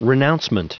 Prononciation du mot renouncement en anglais (fichier audio)
Prononciation du mot : renouncement